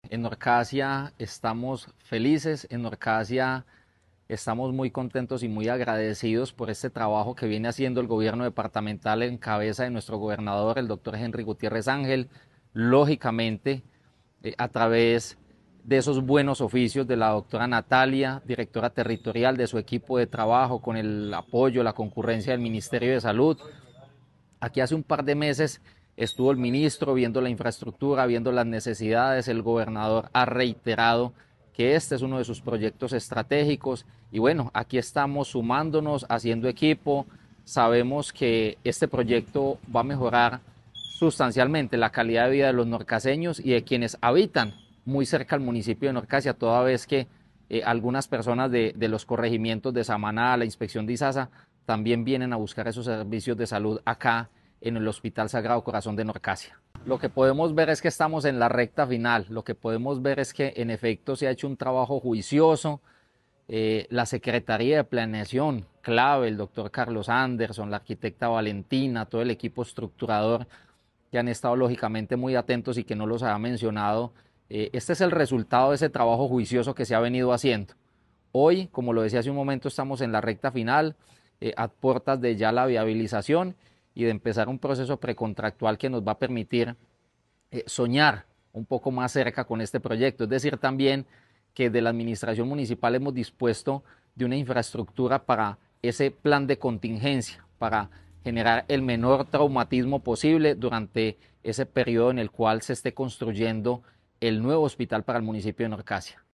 Diego Fernando Alzate, alcalde de Norcasia.